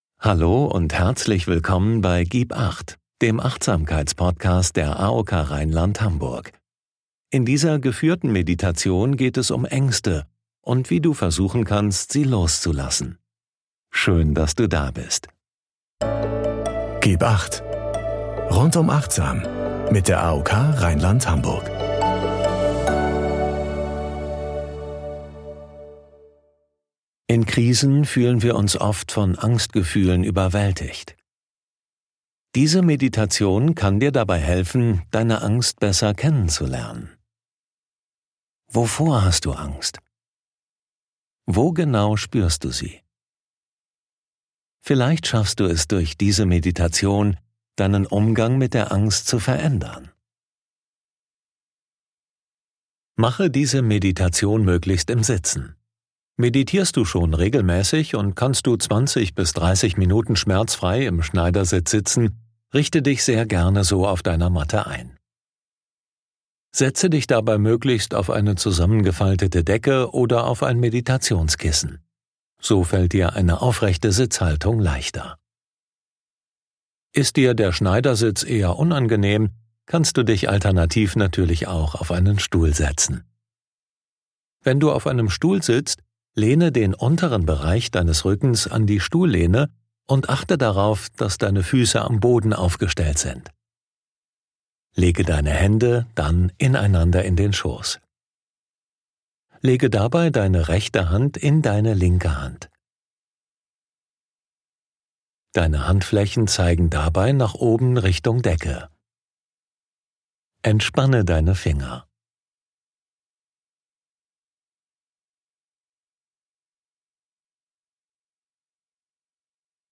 Beschreibung vor 5 Jahren Diese geführte Meditation ist genau dann richtig für dich, wenn du dich aktuell in einer stressigen Zeit befindest. Du kannst lernen, wie du deine Ängste nach und nach loslassen kannst: Beobachte deine Ängste aus neutraler Perspektive, nimm sie an und kämpfe nicht dagegen an!